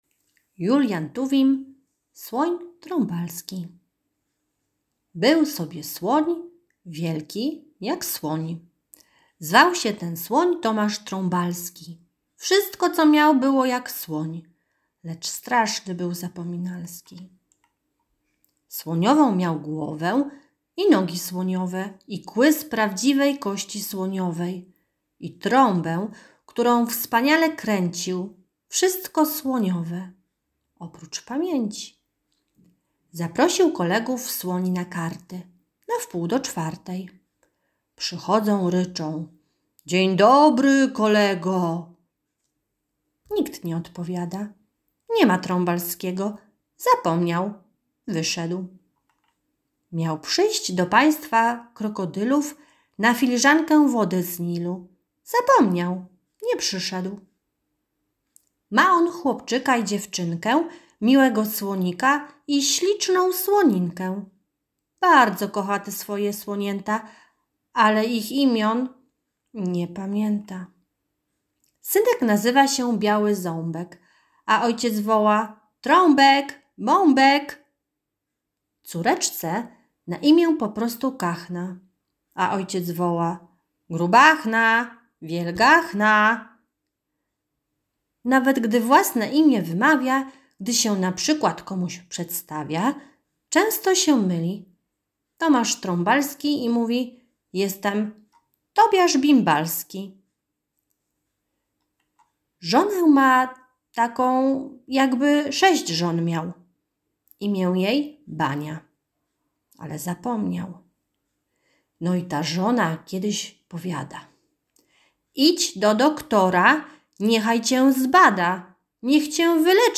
Wiersze